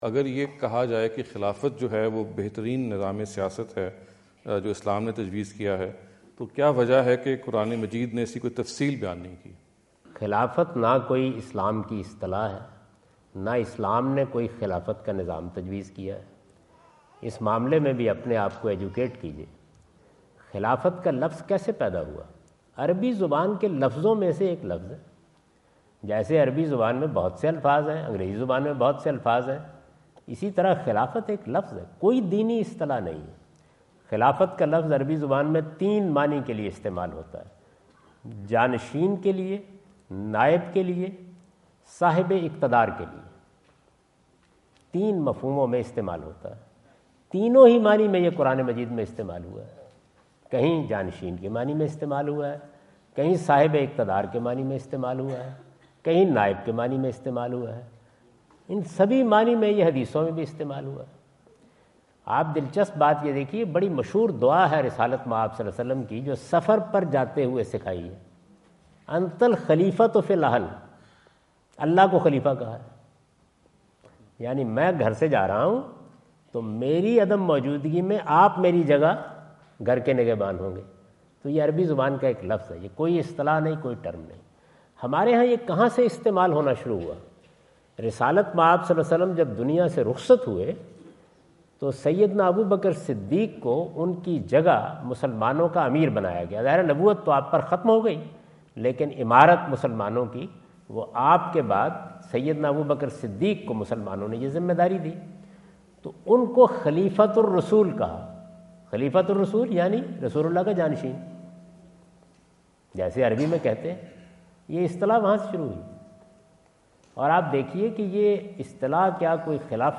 Category: English Subtitled / Questions_Answers /
In this video Javed Ahmad Ghamidi answer the question about "why Quran is silent about caliphate system?" asked at The University of Houston, Houston Texas on November 05,2017.